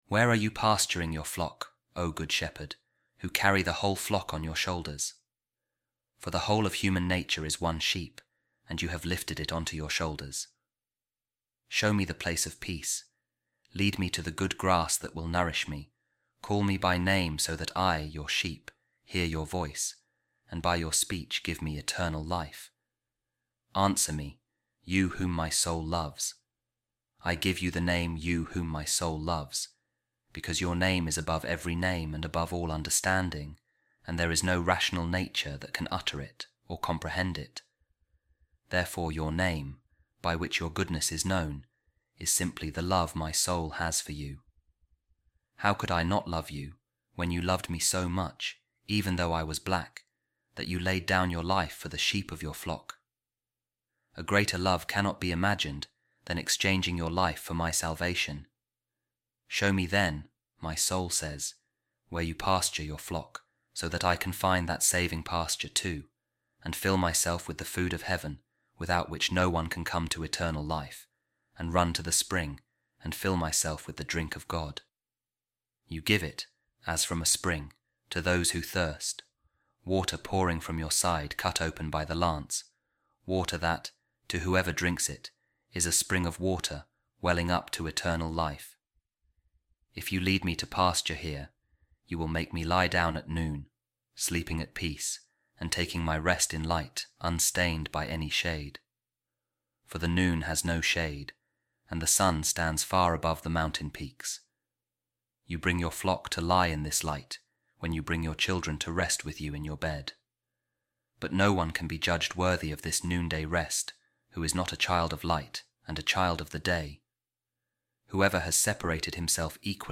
Audio Daily Bible